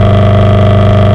Buggy_Med.wav